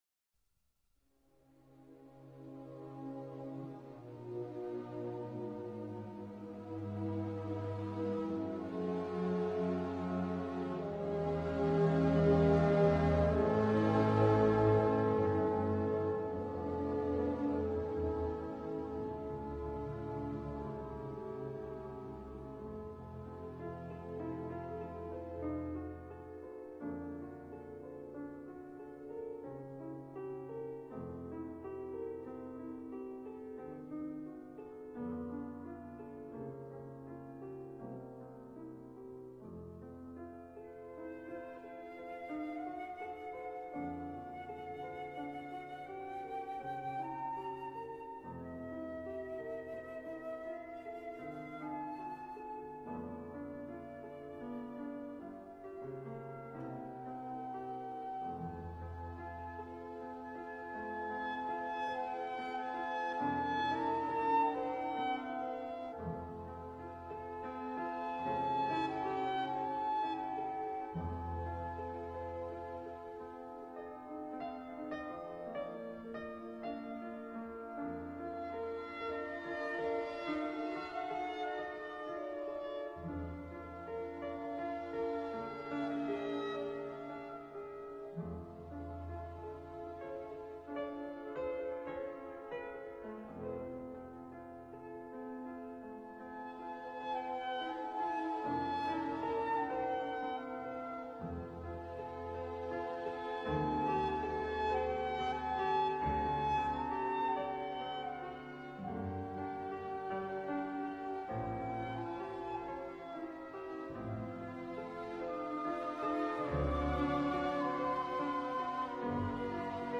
rachmaninov-piano-concerto-no-2-in-c-minor-op-18-ii-adagio-sostenuto.mp3